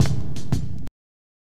KICK HAT.wav